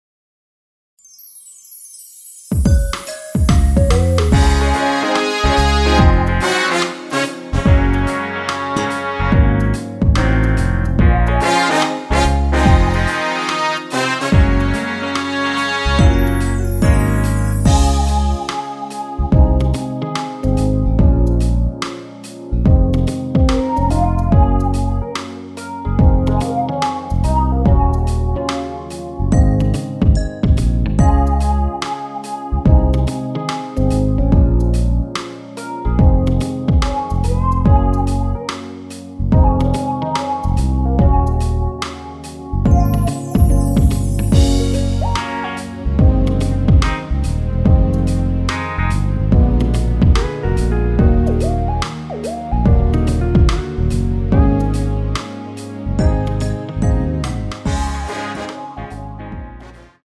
원키에서(-4)내린 MR입니다.
Db
앞부분30초, 뒷부분30초씩 편집해서 올려 드리고 있습니다.
중간에 음이 끈어지고 다시 나오는 이유는